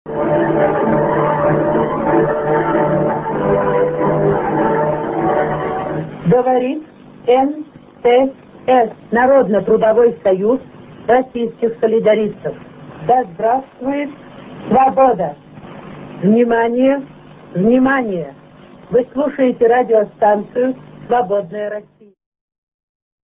Радио Свободная Россия Германия, 28м, 1970-е